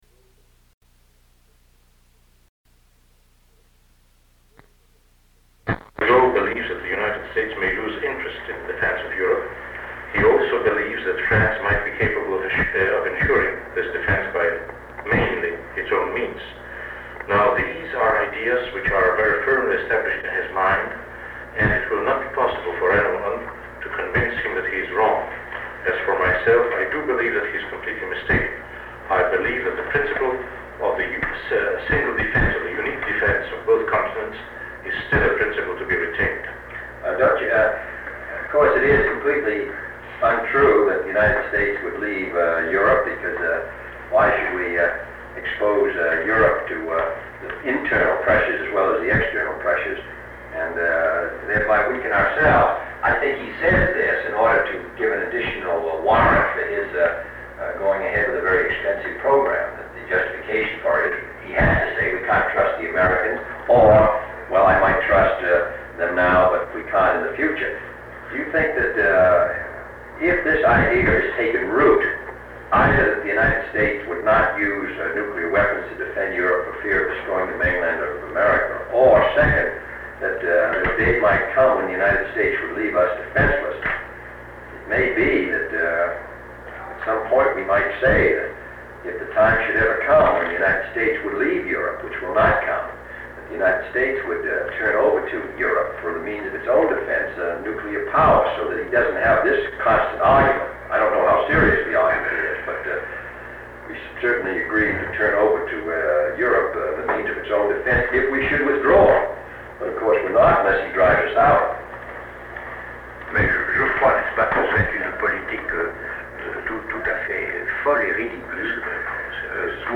Sound recording of part of a meeting held on May 28, 1963, between President John F. Kennedy and Belgian Minister of Foreign Affairs Paul-Henri Spaak. They discuss relations between the United States and Europe, the Multilateral Force (MLF), and President Kennedy’s upcoming trip to Europe. Mr. Spaak speaks in French and is the main speaker at the meeting. President Kennedy’s interpreter can be heard only in whispers, making it difficult to discern any content.
The recording of this meeting ends abruptly and continues on Tape 90.